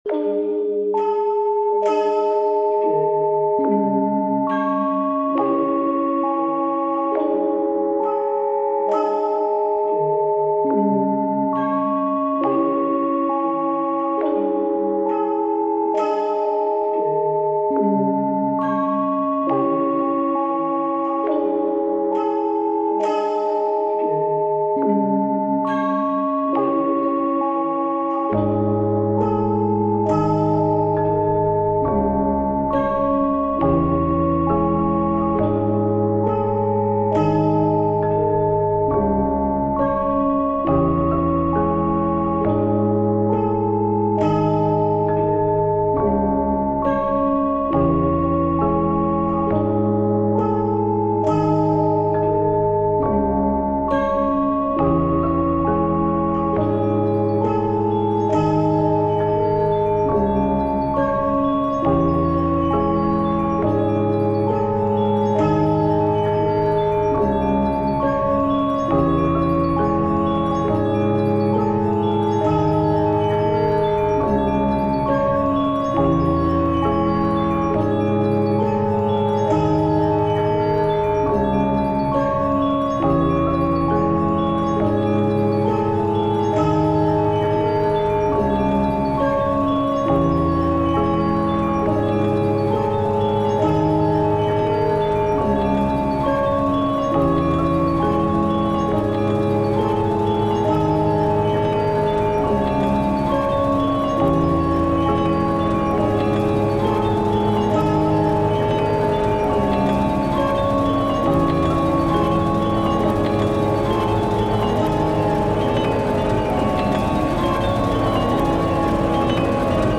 タグ: ドキドキ/緊張感 ホラー/怖い 不気味/奇妙 寂しい/悲しい 怪しい 暗い コメント: ホラー色強めなBGM。